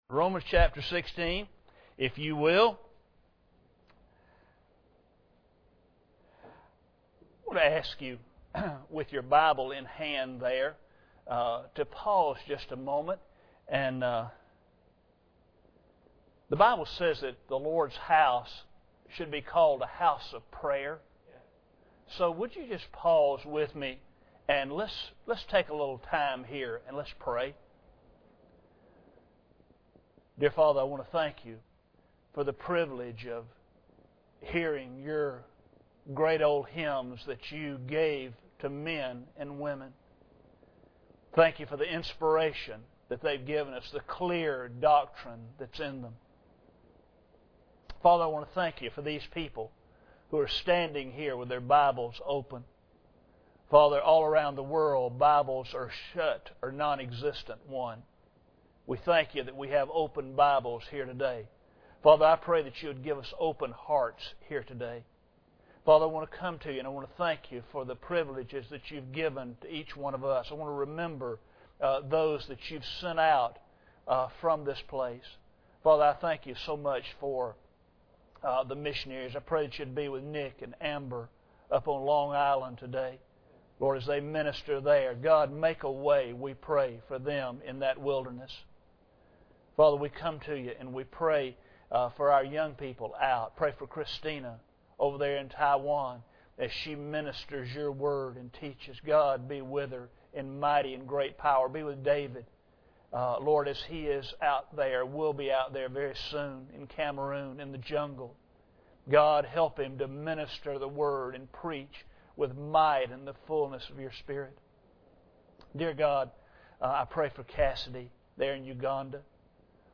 Passage: Romans 16:17-20 Service Type: Sunday Morning